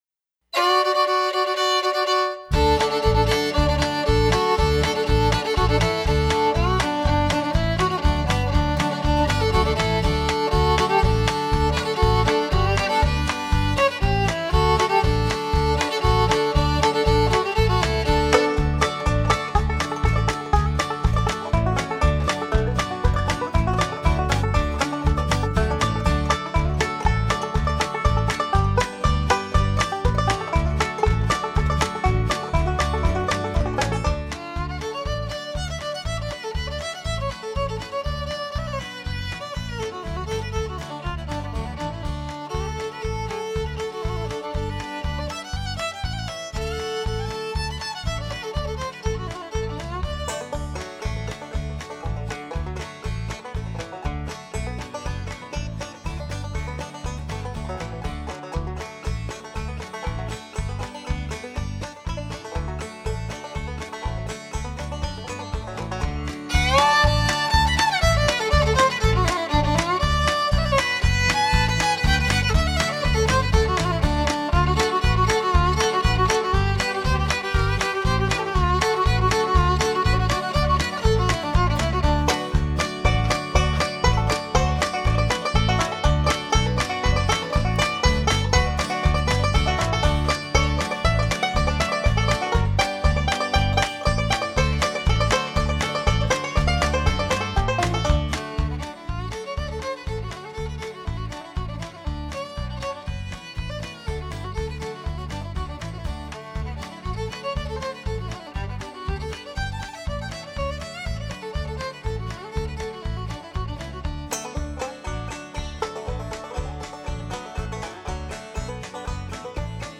So this coming Independence Day, don your patriotic finest, grab some sort of music-making, noise-producing objects, and march around with your child to this Kindermusik rendition of “Sourwood Mountain,” a traditional American dancing song.